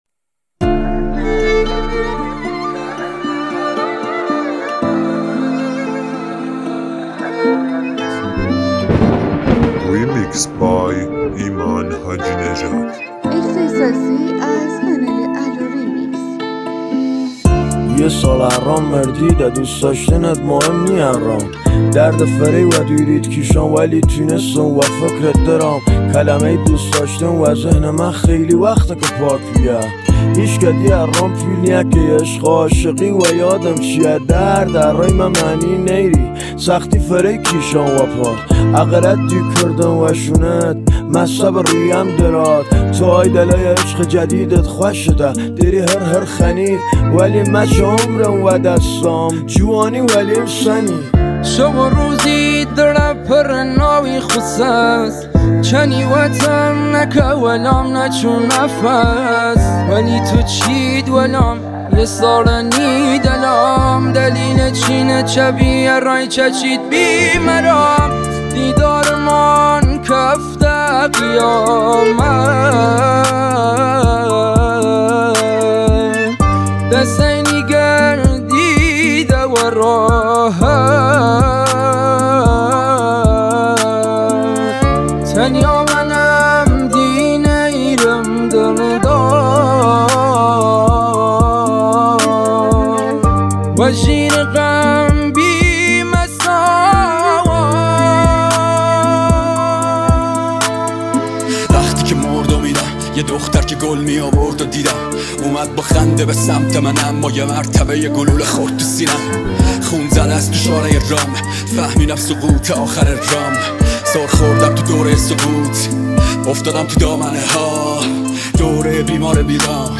دانلود ریمیکس جدید رپ